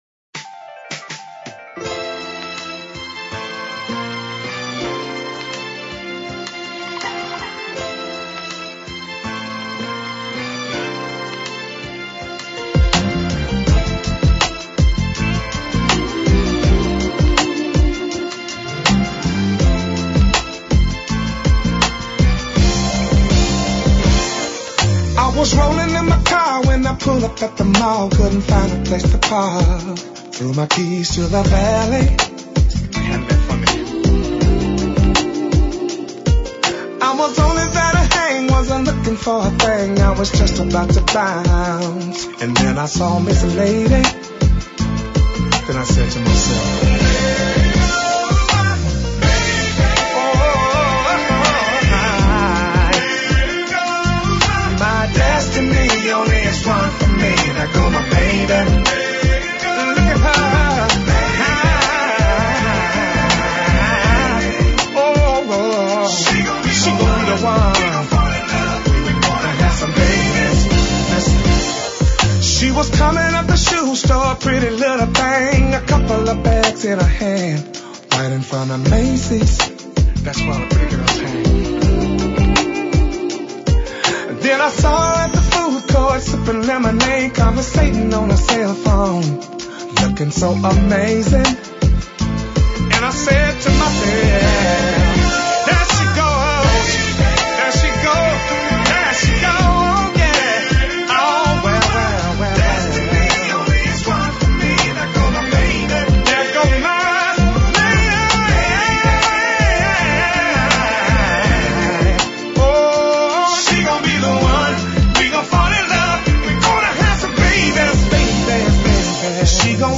Rhythm and Soul